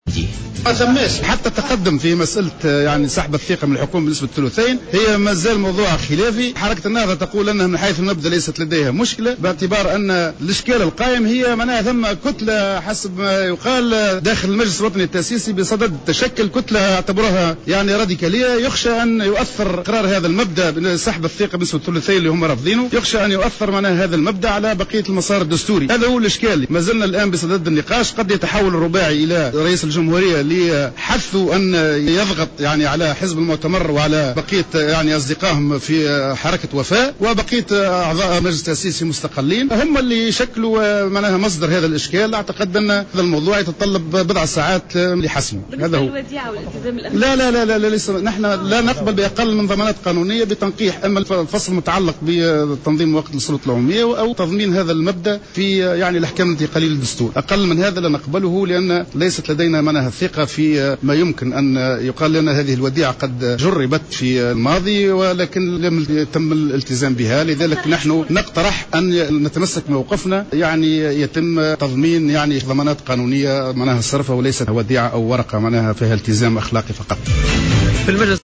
قال المنسق العام للتيار الشعبي زهير حمدي اليوم الخميس 23 جانفي 2014 في تصريح لجوهرة "اف ام" أن الحوار الوطني لم يحرز أي تقدم حتى الان في مسألة سحب الثقة من الحكومة بنسبة الثلثين وأنه مازال موضوعا خلافيا .